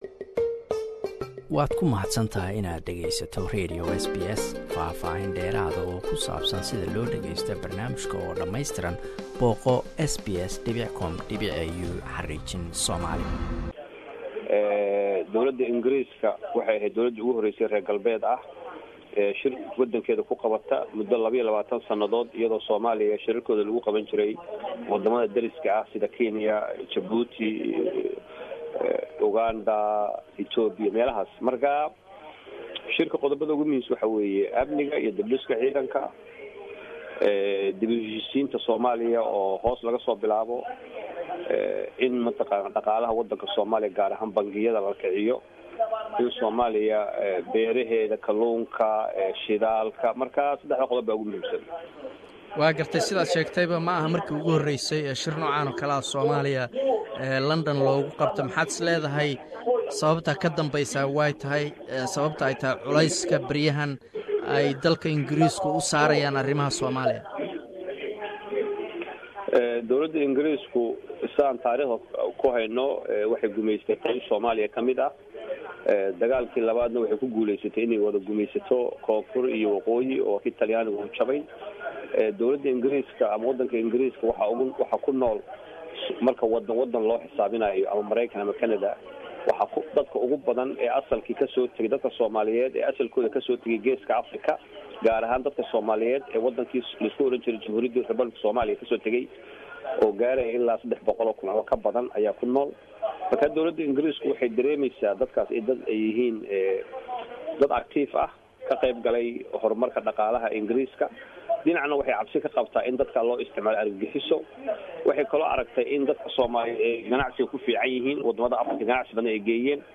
Waxaan ka waraysnay